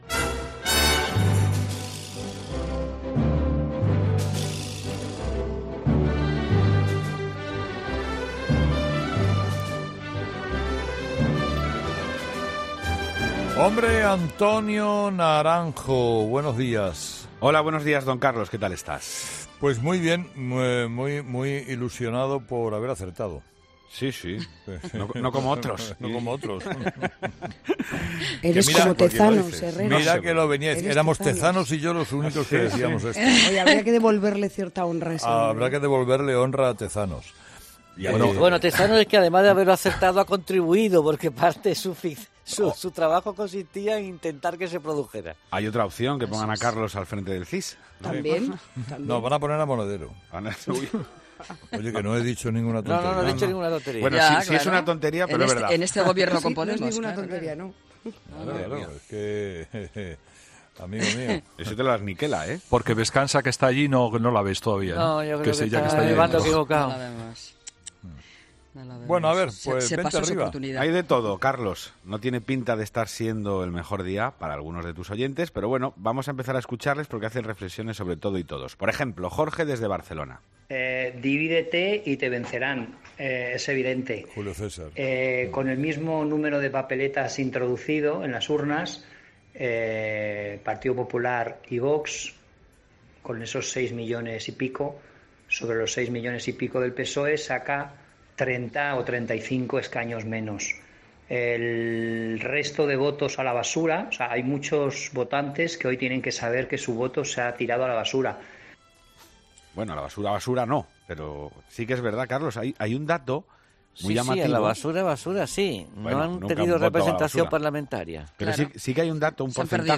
La tertulia de los oyentes es el espacio que Herrera da a sus seguidores para que opinen sobre los temas de actualidad.